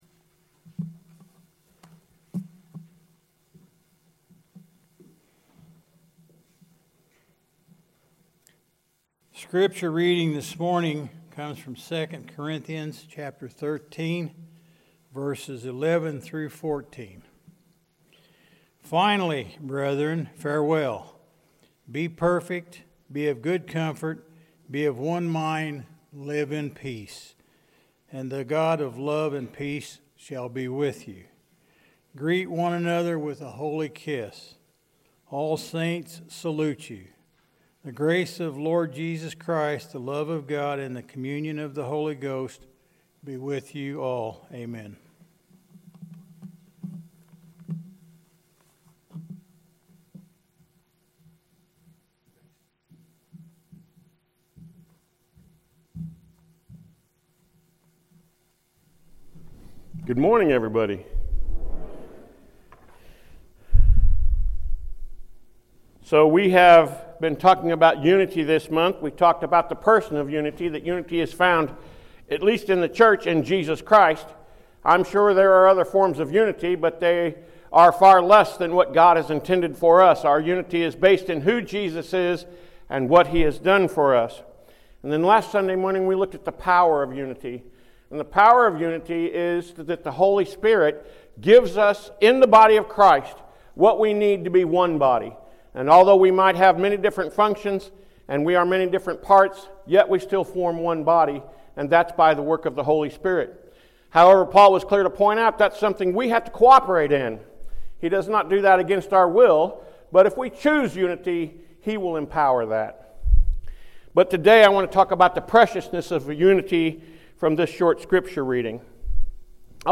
Sermons | Central Church of Christ